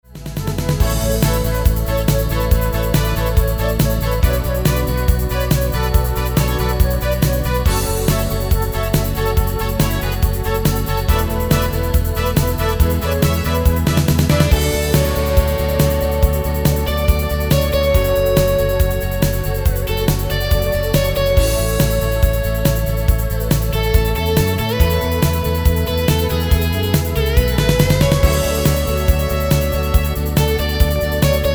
Tempo: 140 BPM. hudba
Skladba je součástí kategorie  a také: Rock Czech-Slovak,
MP3 with melody DEMO 30s (0.5 MB)zdarma